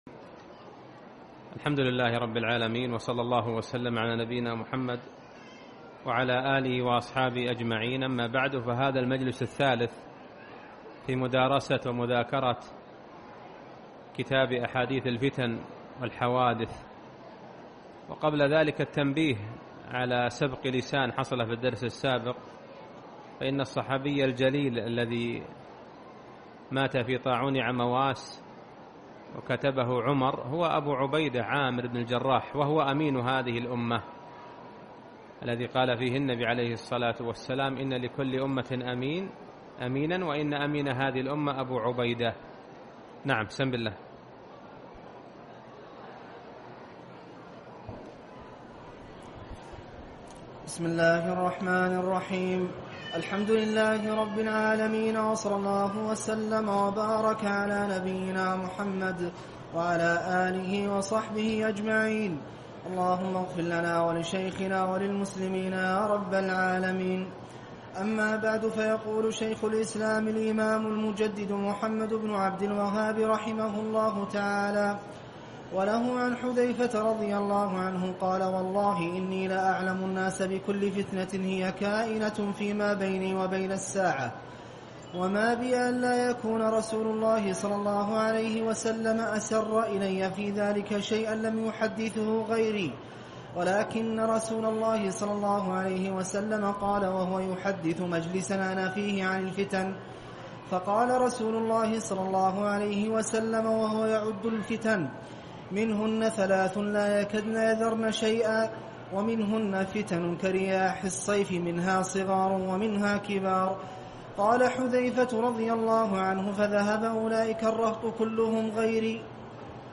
الدرس الثالث - شرح أحاديث الفتن والحوادث